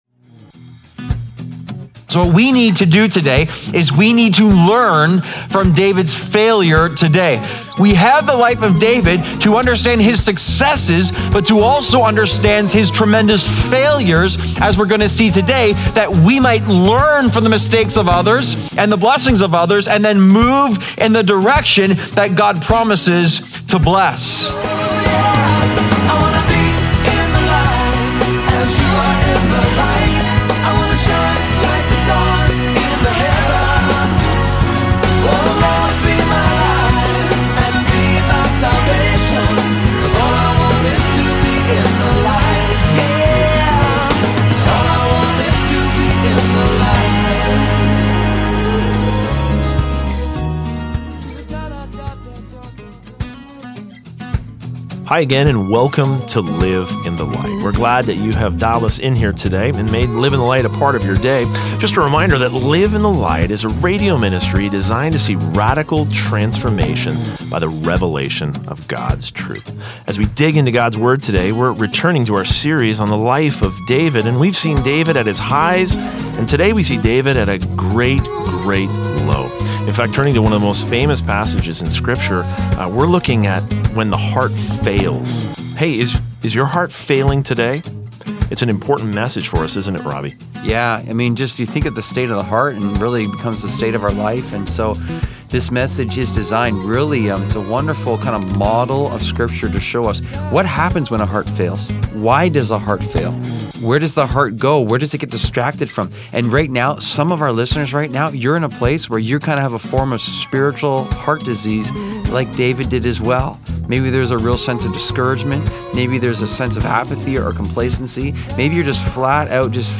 Live in the Light Daily Broadcast Heart Failure (Part 1 of 2) Feb 25 2026 | 00:29:30 1x 00:00 / 00:29:30 Subscribe Share Apple Podcasts Spotify Overcast RSS Feed Share Link Embed